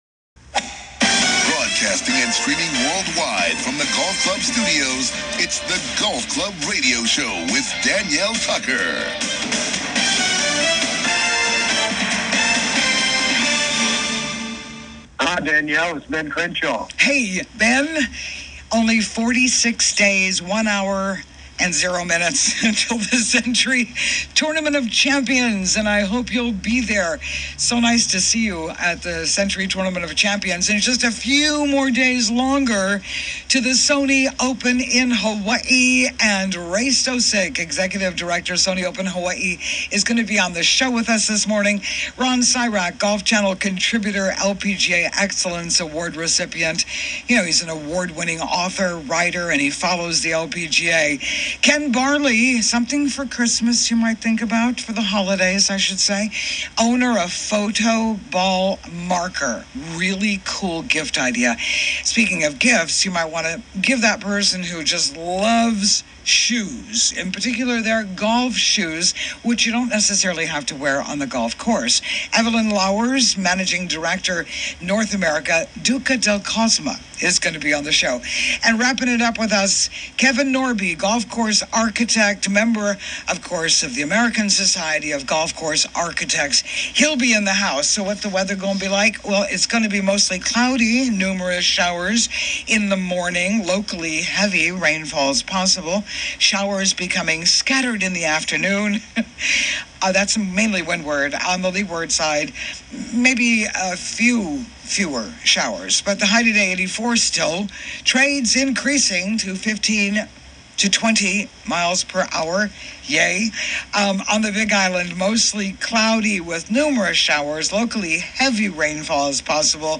COMING TO YOU LIVE FROM THE GOLF CLUB STUDIOS ON LOVELY OAHU�s SOUTH SHORE � WELCOME INTO THE GOLF CLUB HOUSE!